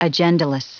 Prononciation du mot agendaless en anglais (fichier audio)
Prononciation du mot : agendaless